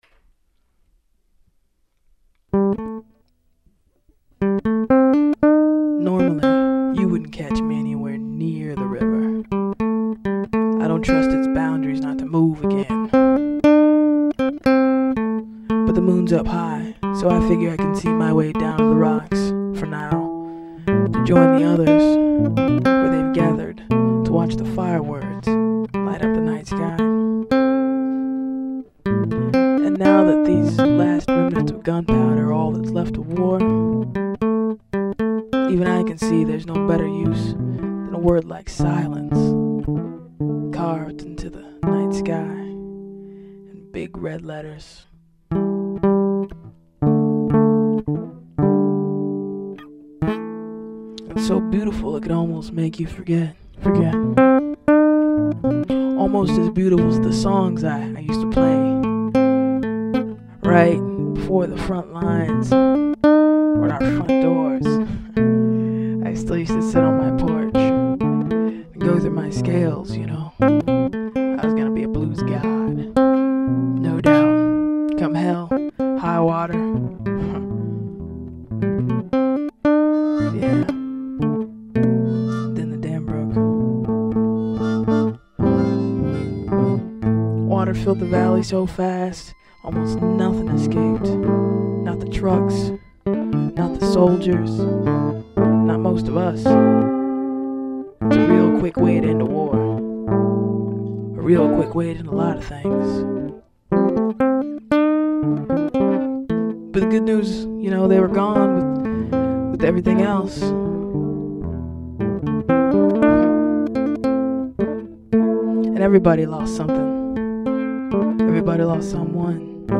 Music
evocative jazz poem